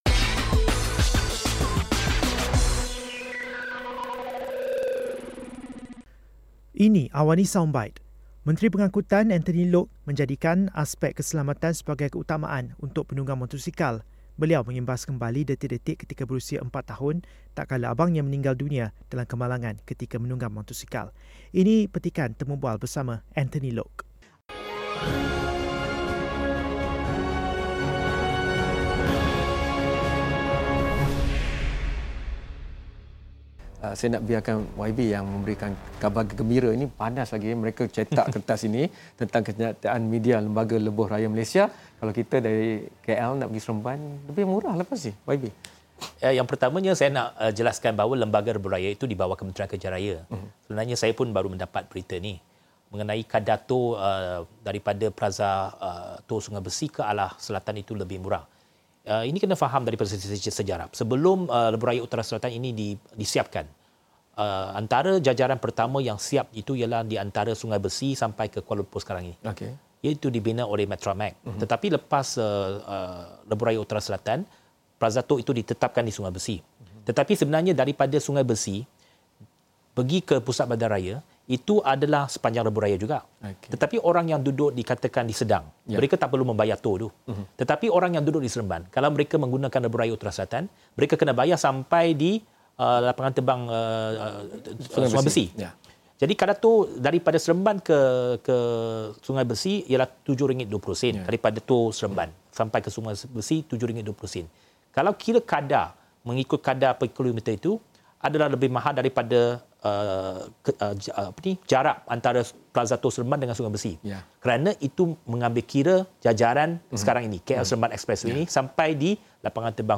Ini petikan temubual bersama Anthony Loke.